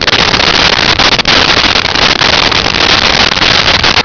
Sfx Surface Steelecho Loop
sfx_surface_steelecho_loop.wav